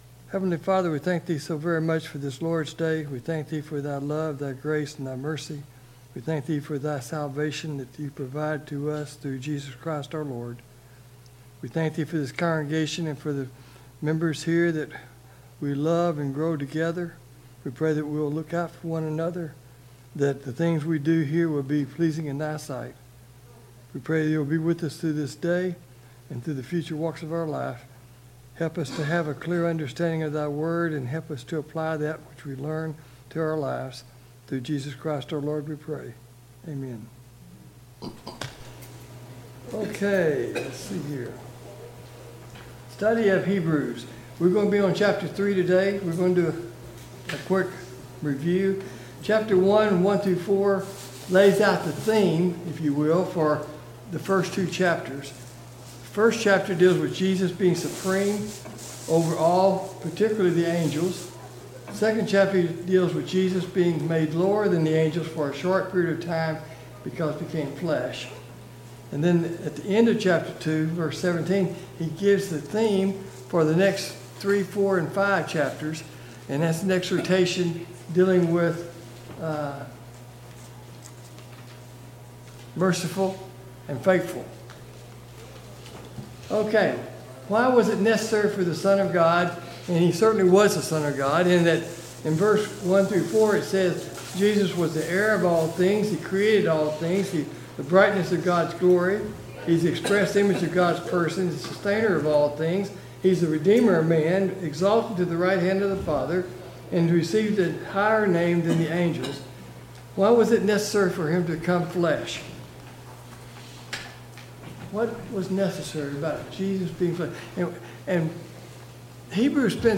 Study of Hebrews Passage: Hebrews 2-3 Service Type: Sunday Morning Bible Class « 22.